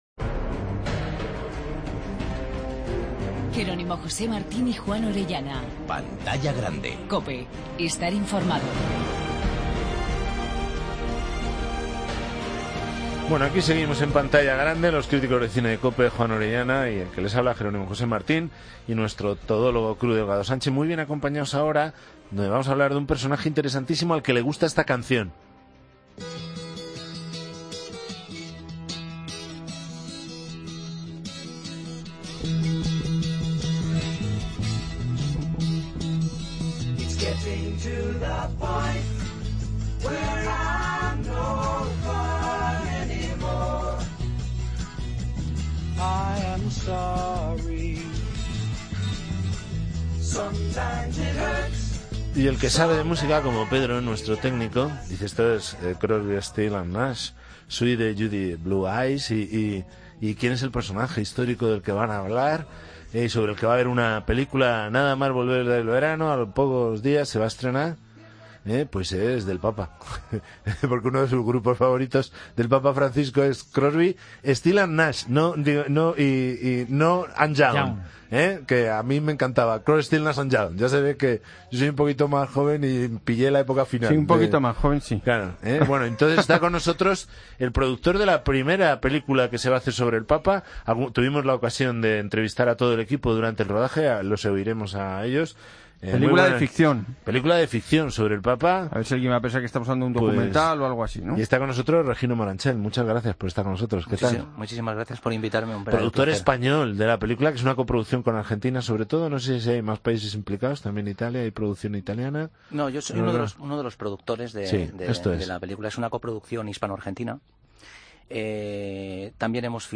E ilustramos el coloquio con las canciones y piezas musicales favoritas del Papa Francisco.